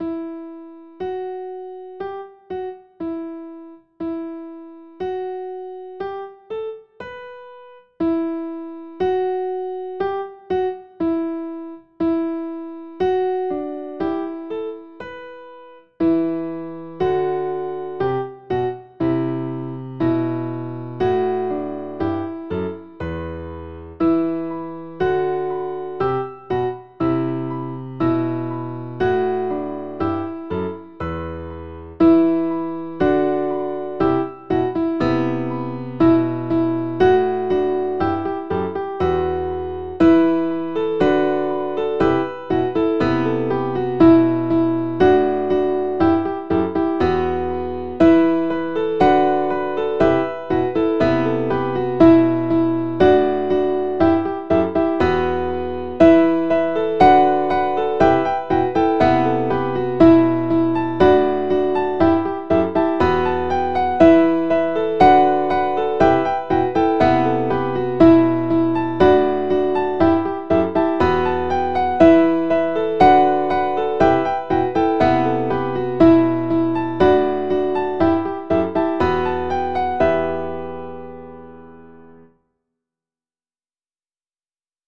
quick and easy piano ensembles for small to large groups